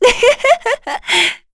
Yuria-Vox_Happy3_kr.wav